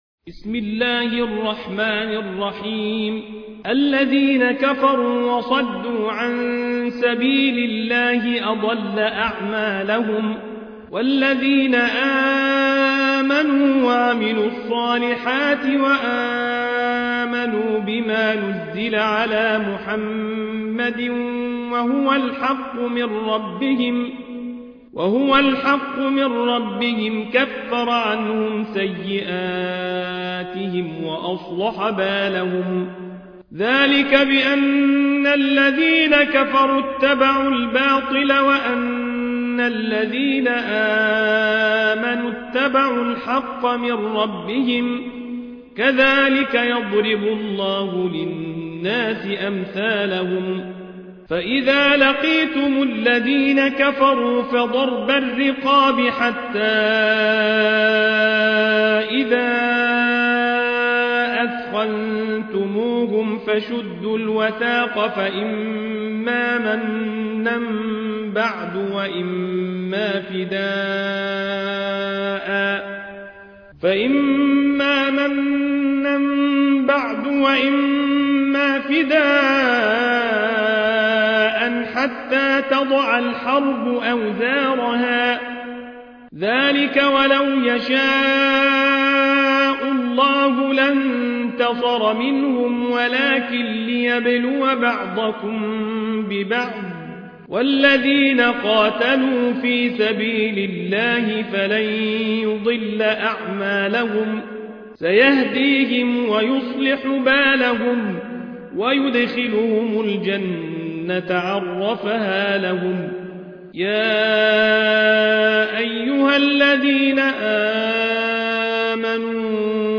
High Quality Quranic recitations and Islamic Lectures from selected scholars